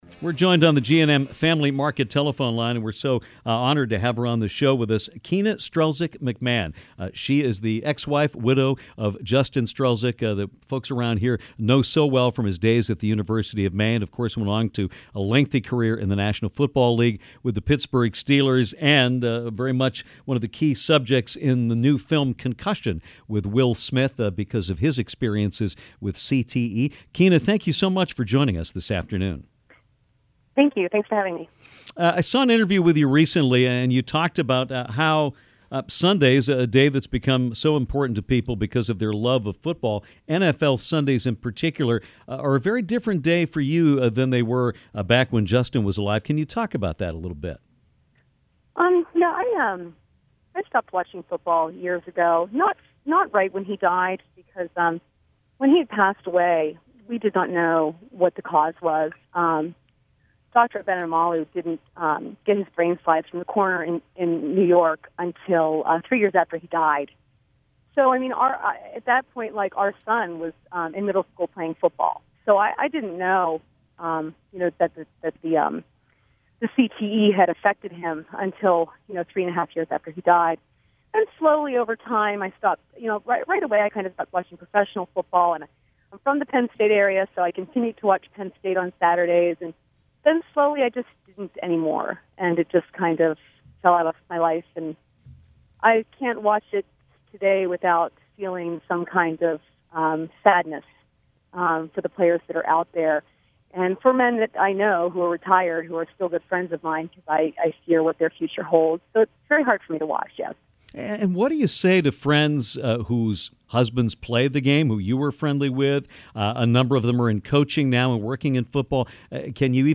It is interviews like this one that make me especially proud to be a small part of a great show.